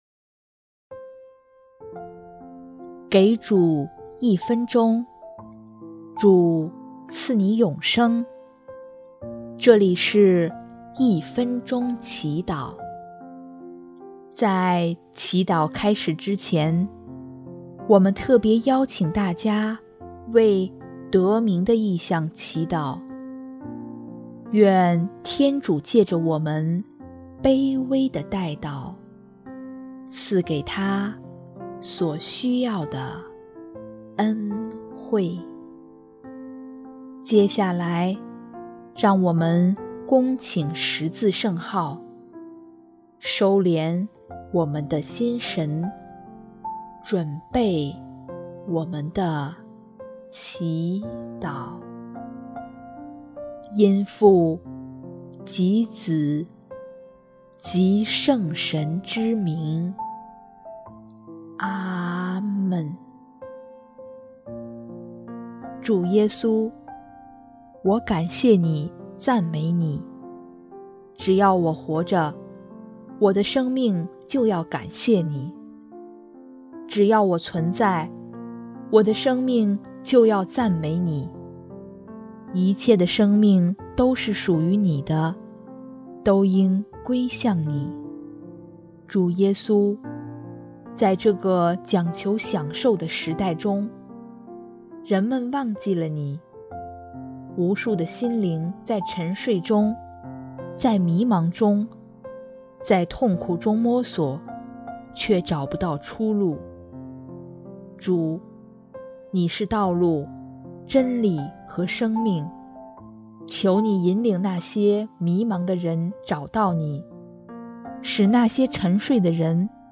【一分钟祈祷】|10月24日 明智为先，醒悟中等待！